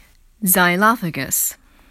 発音に注意して下さい。